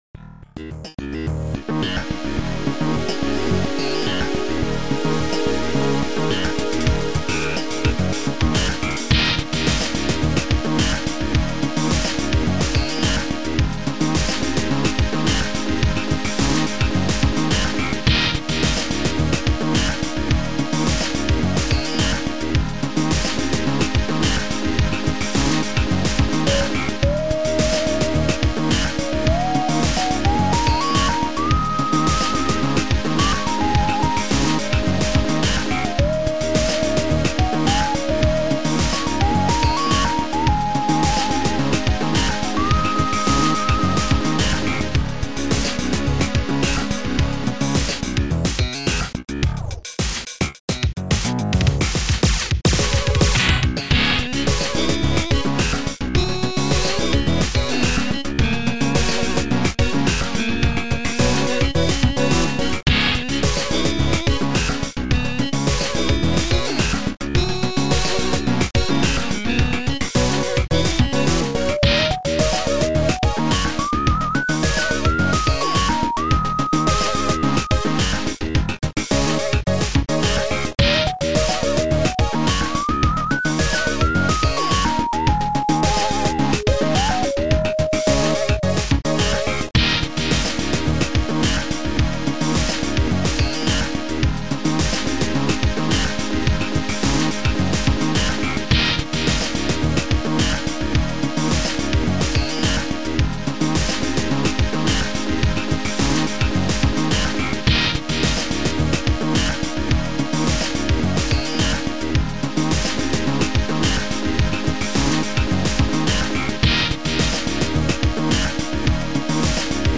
Music Competition
Sound Format: Noisetracker/Protracker
Sound Style: Funky Synth Pop